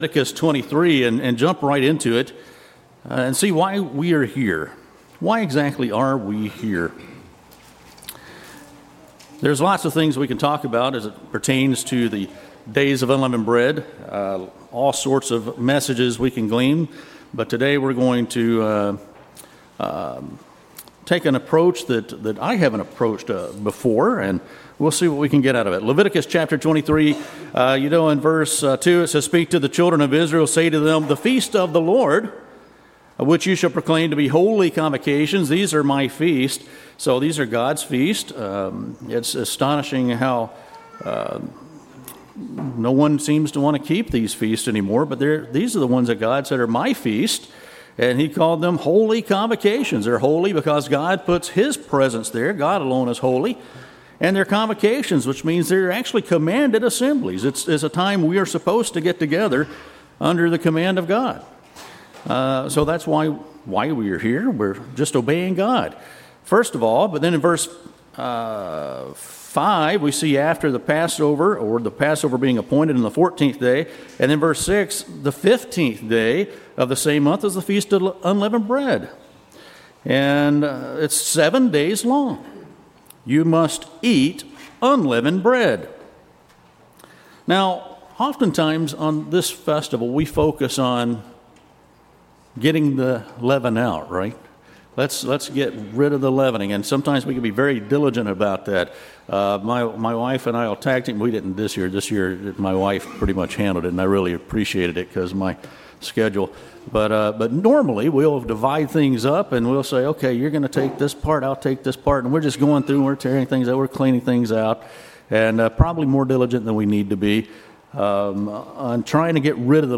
Last Day of Unleavened Bread sermon reflects on the importance and significance of the Spring Holy Day season. We also take an additional focus on replenishing ourselves with good leavening going forward.